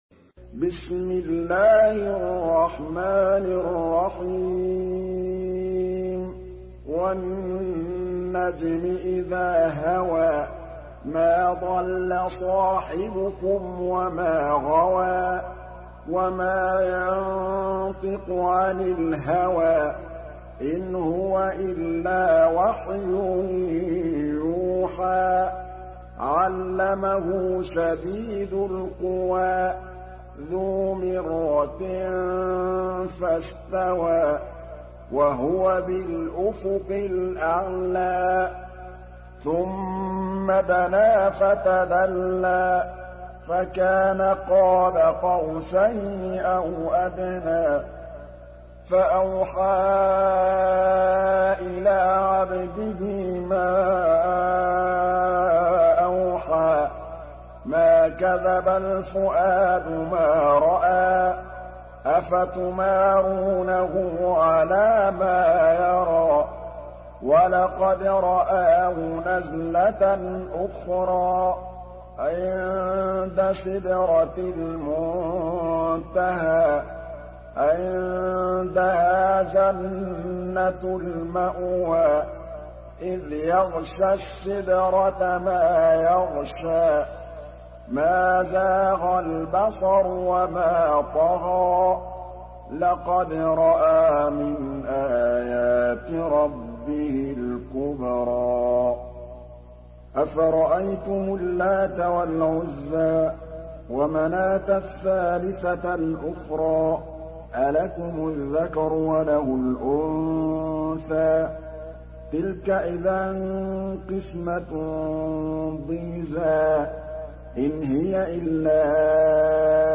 Surah An-Najm MP3 by Muhammad Mahmood Al Tablawi in Hafs An Asim narration.
Murattal Hafs An Asim